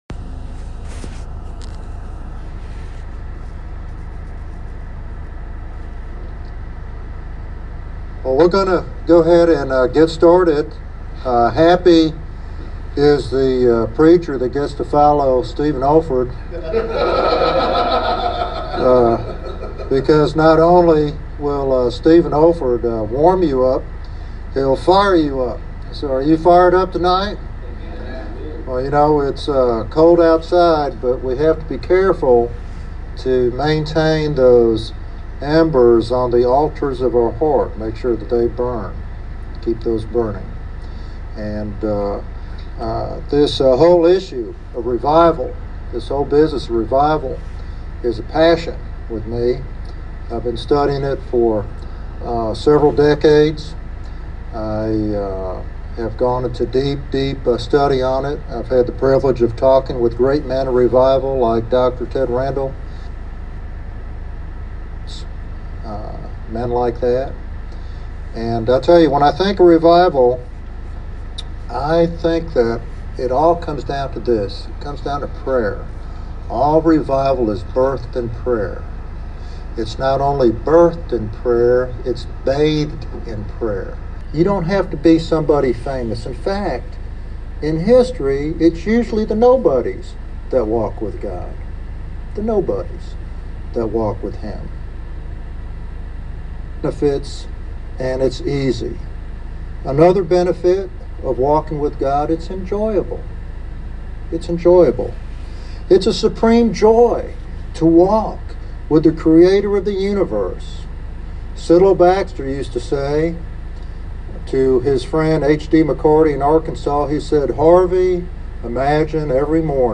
This sermon is a call to spiritual renewal and a deeper relationship with God for those in ministry.